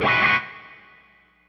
guitar stab.wav